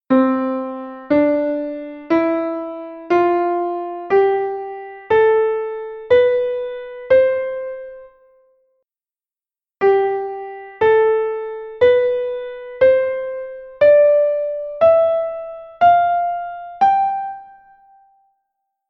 htrEscoita a diferenza existente a nivel auditivo. Primeiro a escala de Dó M e logo a de Sol: